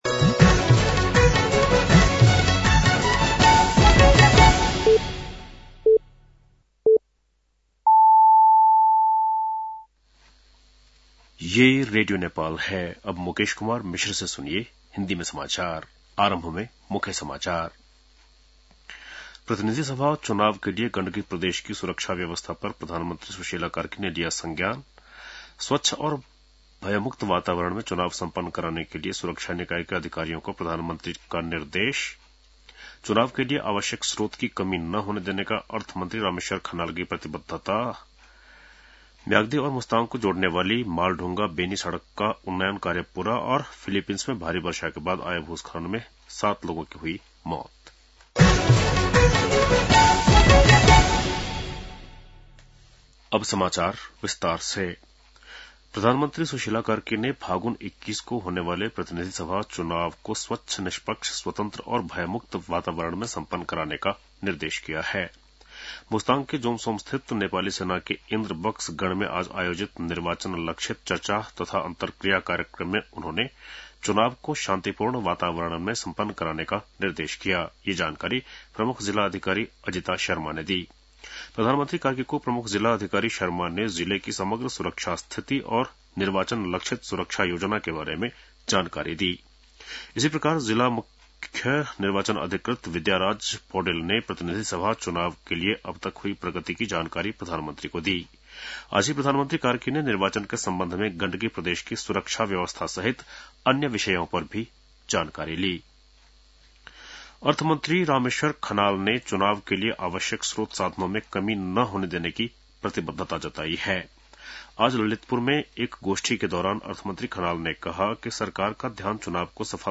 बेलुकी १० बजेको हिन्दी समाचार : ८ फागुन , २०८२